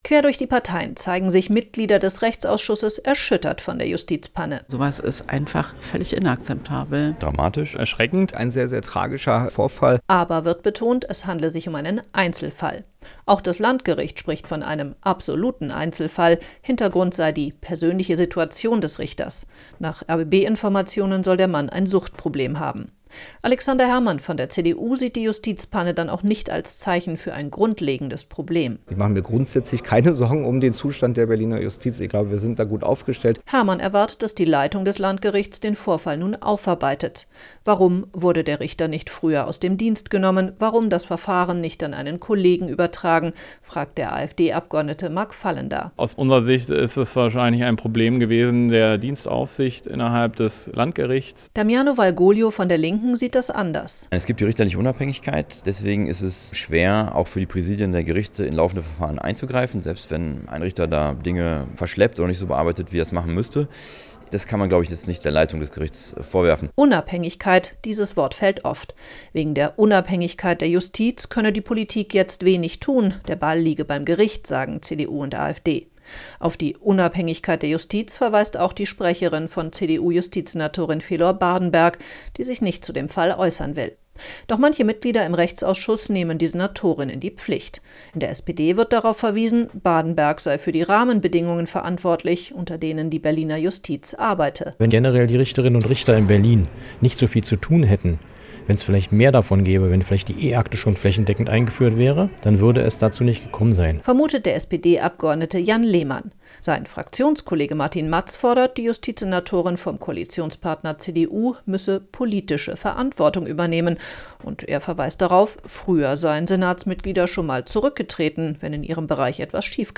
Diskussion: Verurteilter Vergewaltiger auf freiem Fuß